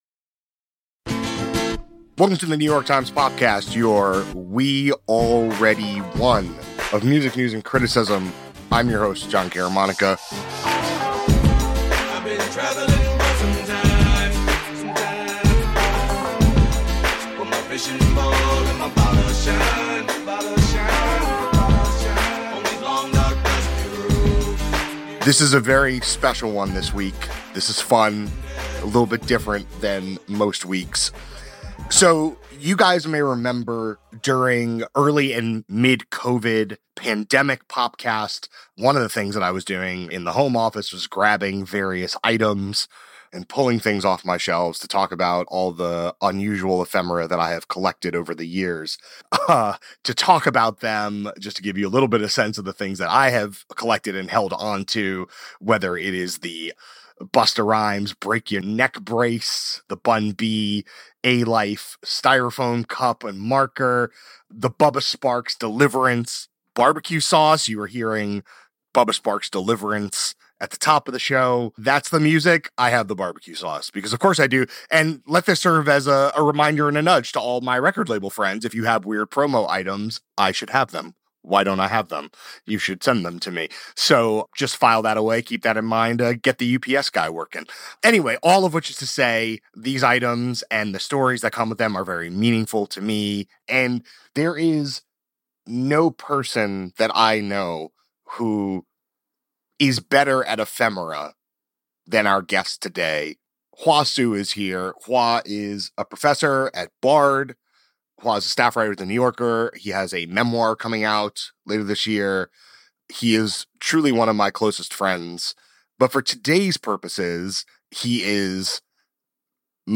A conversation about the urge to accumulate treasured items and the stories objects can hold. Guest: The New Yorker's Hua Hsu.